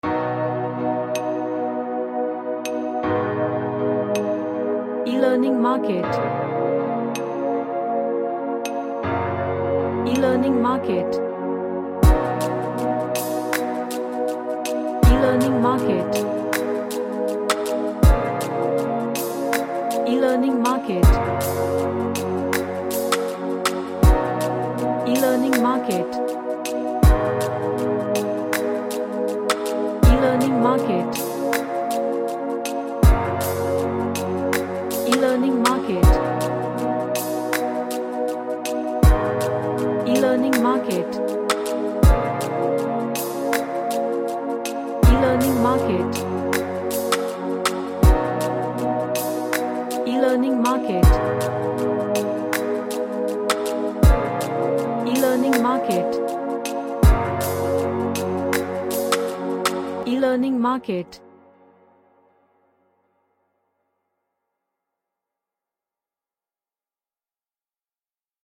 An Ambient track.
Relaxation / Meditation